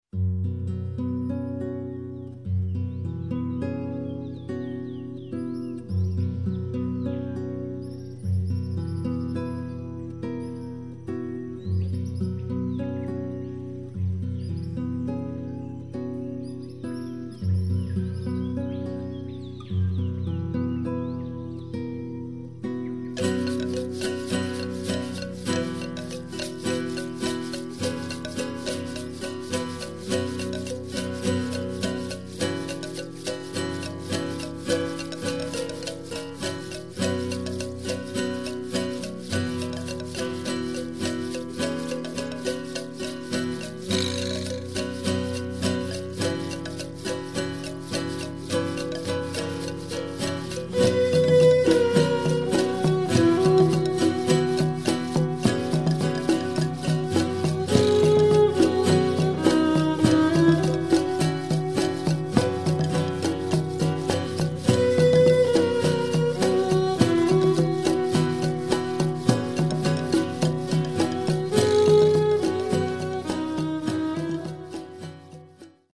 guitarra española